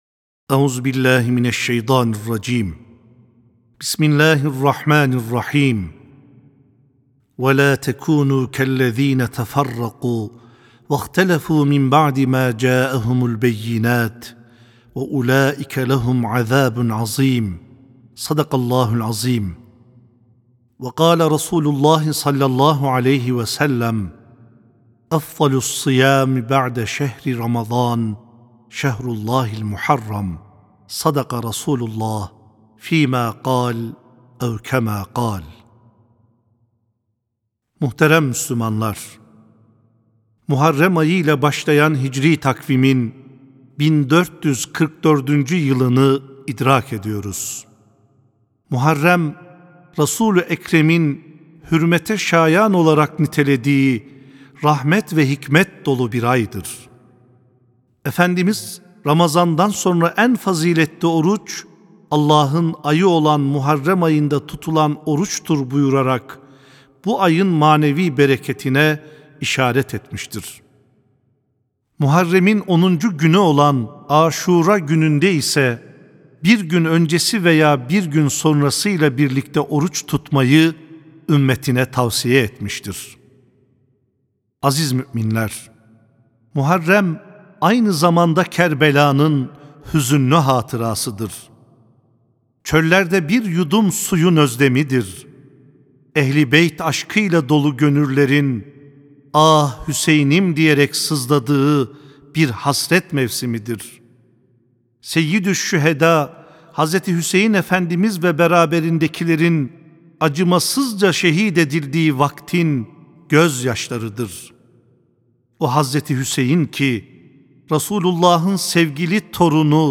CUMA HUTBESİ; MUHARREM AYI VE KERBELÂ
Sesli-Hutbe-Muharrem-Ayı-ve-Kerbela.mp3